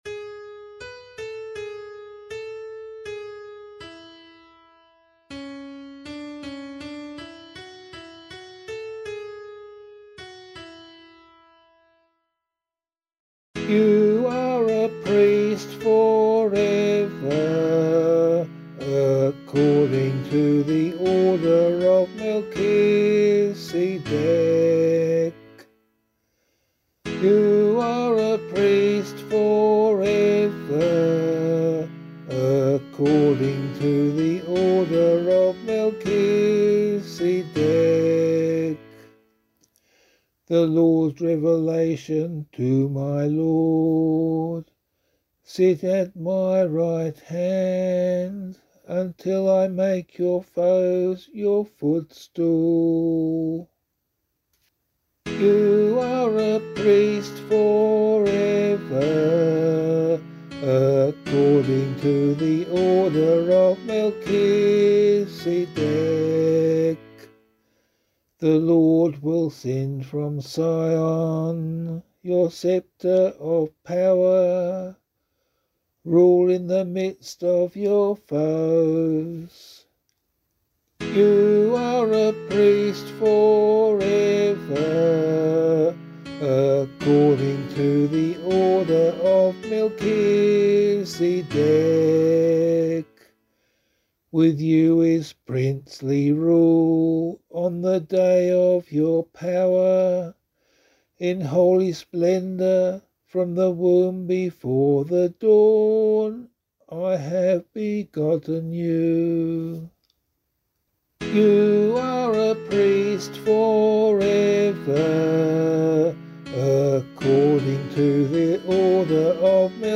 034 Corpus Christi Psalm C [APC - LiturgyShare + Meinrad 8] - vocal.mp3